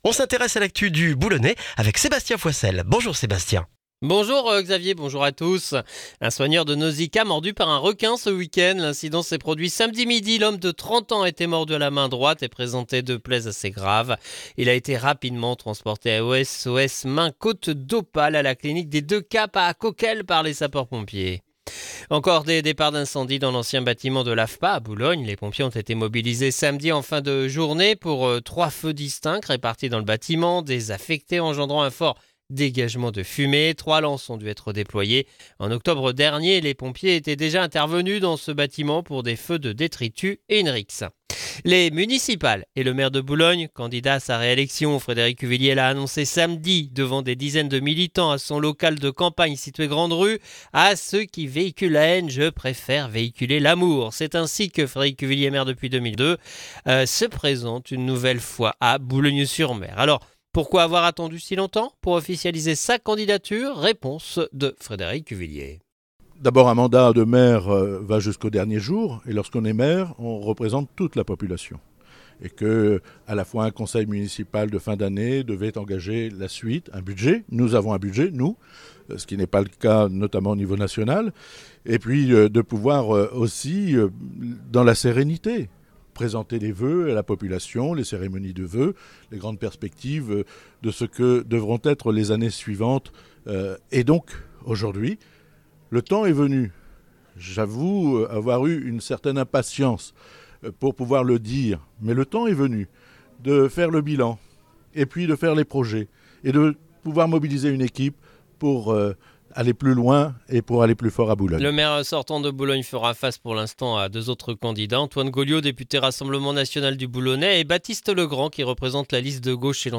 Le journal du lundi 26 janvier dans le Boulonnais